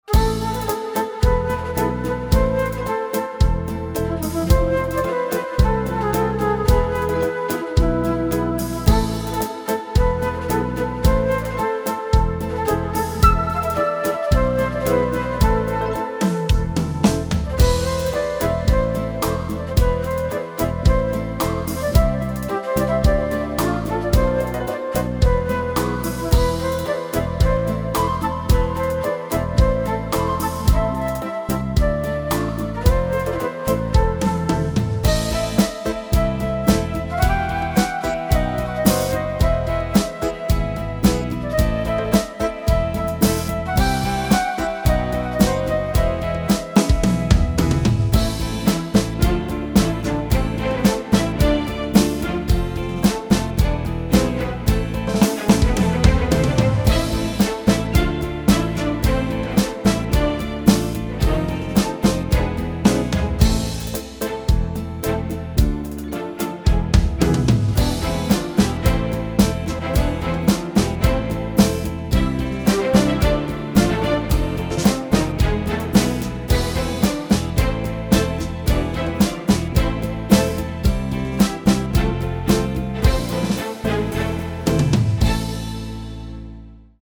Rumba
06 Slow
אסטה-פאנק_01.mp3